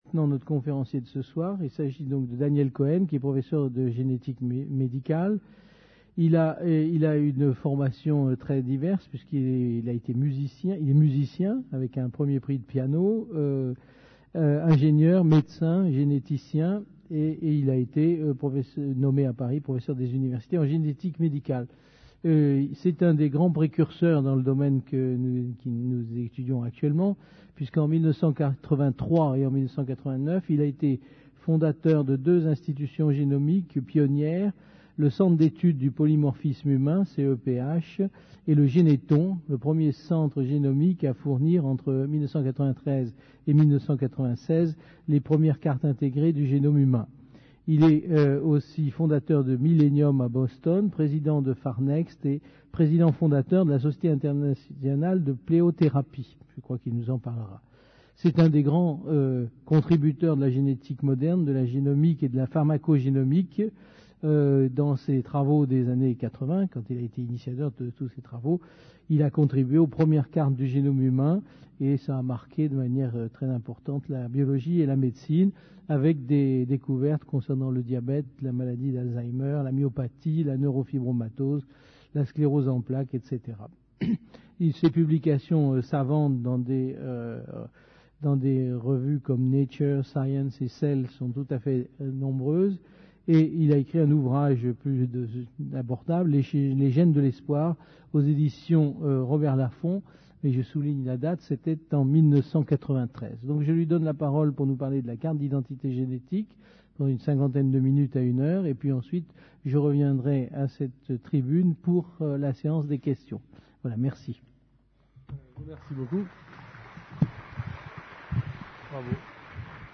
Une conférence du cycle : Qu'est ce que la vie ? Où en est la connaissance du génome ? Par Daniel Cohen, Généticien, fondateur du Généthon, PDG de PharNext